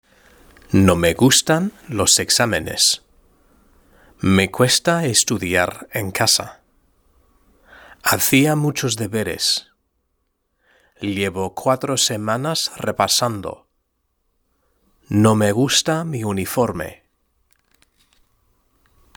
Lectura en voz alta: 1.3 La educación y el trabajo (F) – Lingo Bennies!